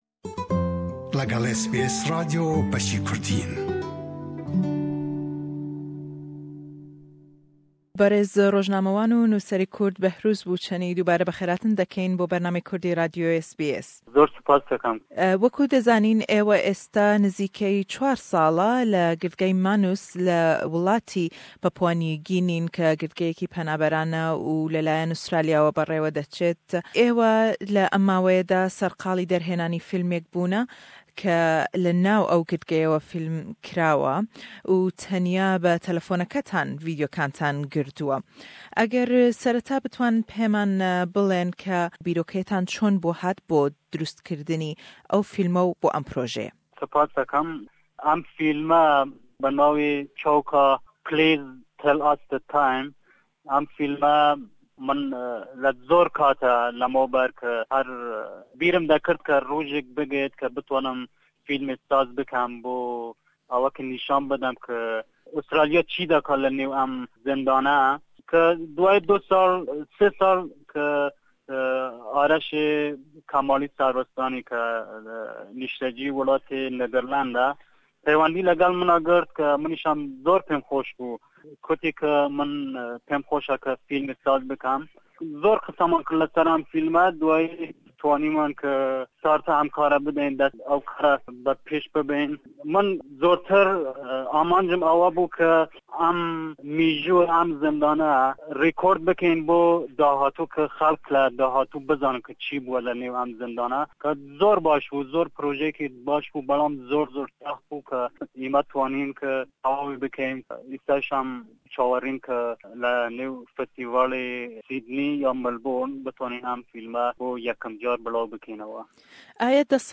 In this interview we ask him about the fillming of the movie which was only done on his mobile phone and in secret.